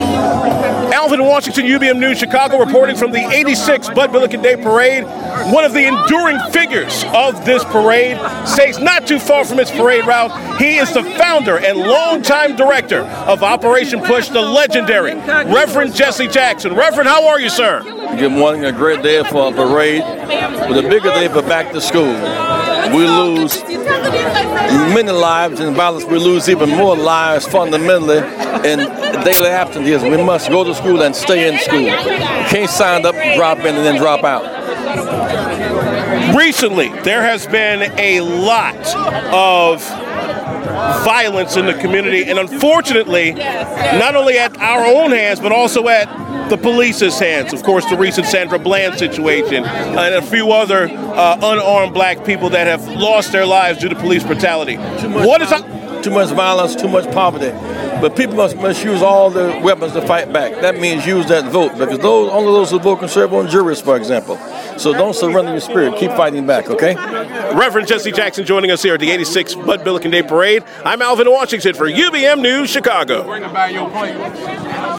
UBM News speaks with Operation PUSH founder Rev. Jesse Jackson at the Bud Billiken parade